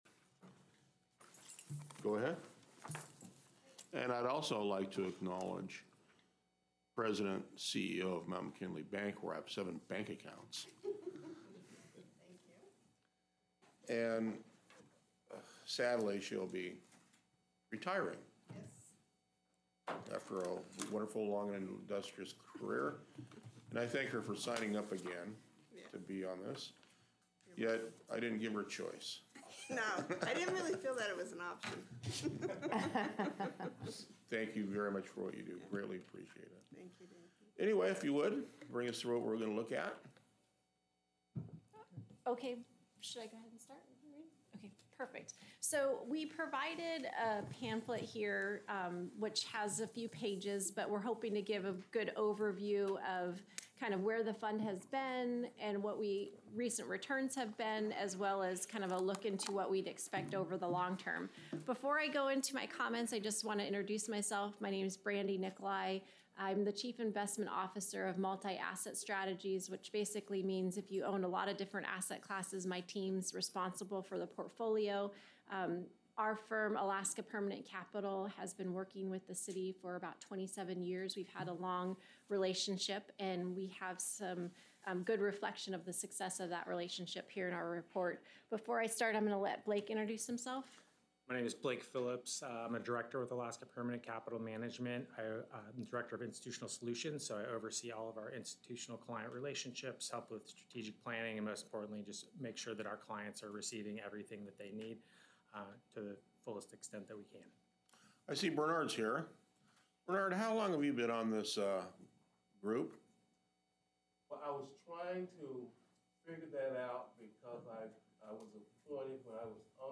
Special Council Work Session - Permanent Fund Review Board Annual Report